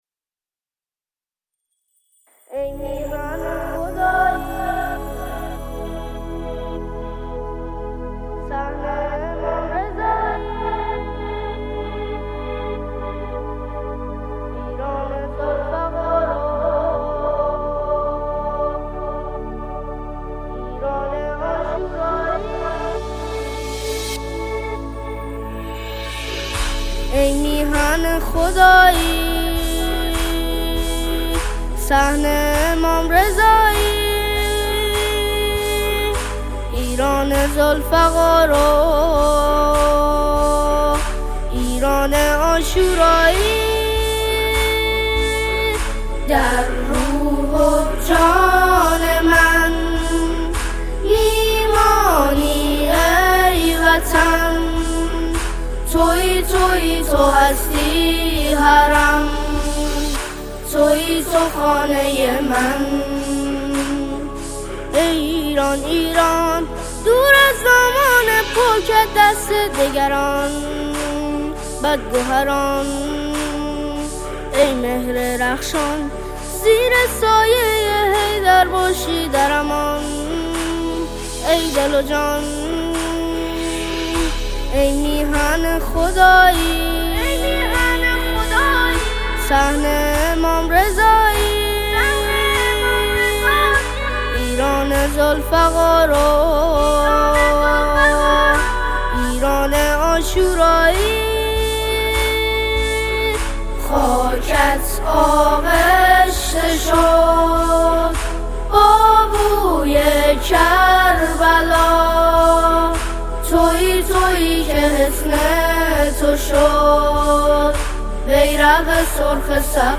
اثری حماسی، پرشور و معنوی
ژانر: سرود